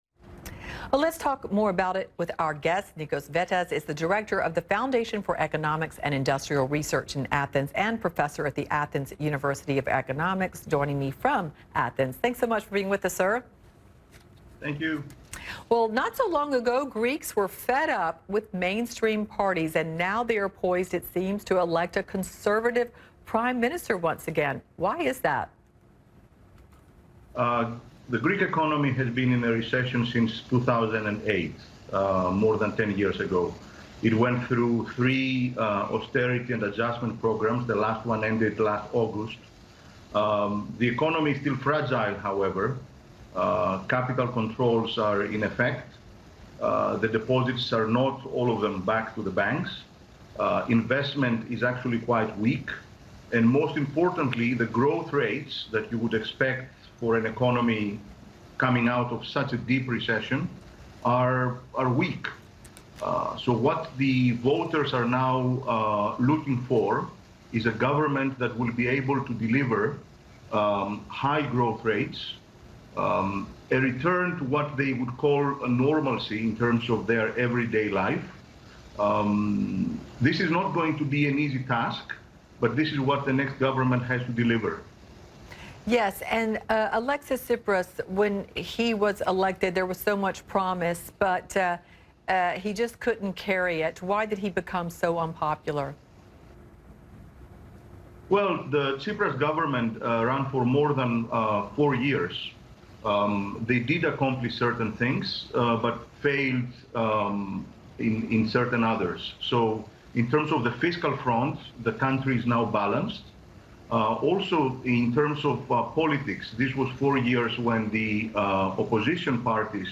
Συνέντευξη στο CNN International